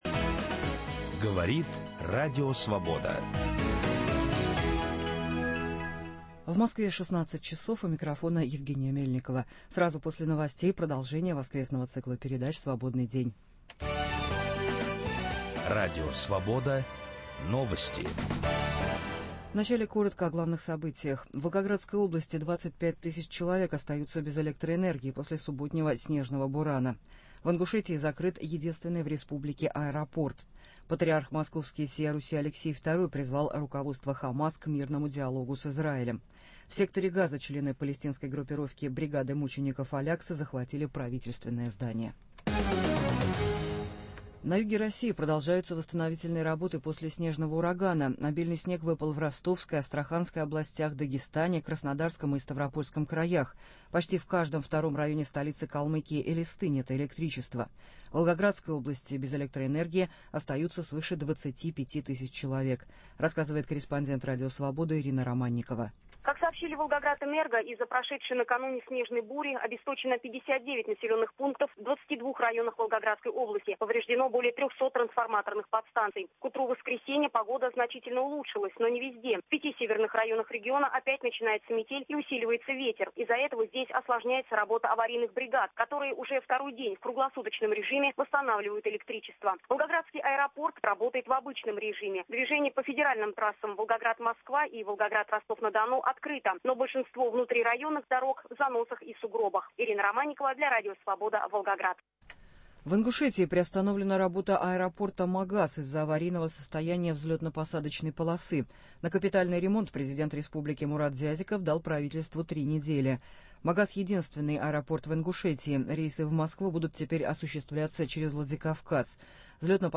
Сложные и драматические взаимоотношения Ахматовой с властью, с сыном Львом Гумилевым, с русской эмиграцией. Звучат голоса из архива Радио Свобода.
Стихи в авторском чтении.